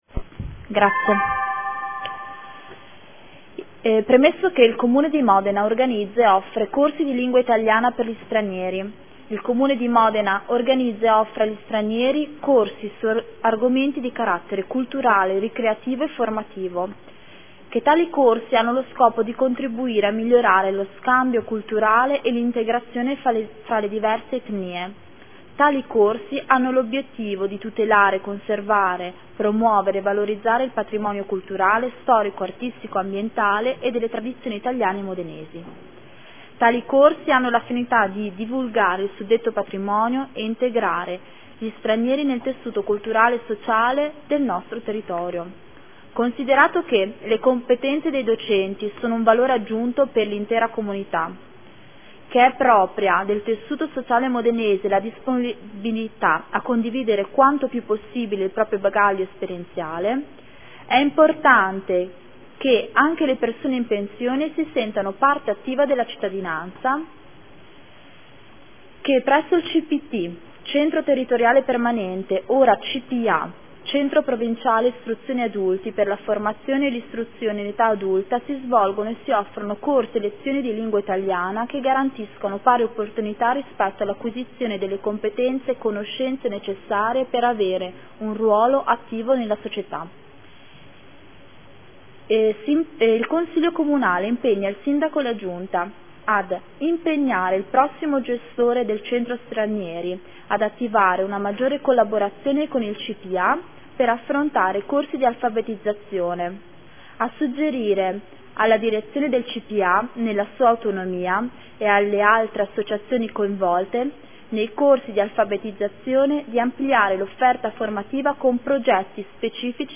Seduta del 26 febbraio. Proposta di deliberazione: Linee di indirizzo per la gestione di attività e progetti rivolti all’integrazione dei cittadini stranieri. Presentazione odg n° 25861 da parte della Consigliera Scardozzi